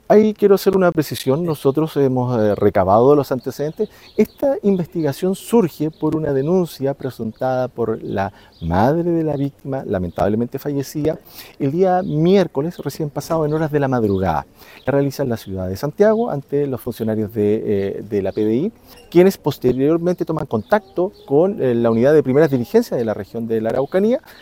El fiscal César Schibar, quien investiga este caso, dijo que será clave en este caso el peritaje de autopsia para determinar la data y la causa de muerte de la víctima.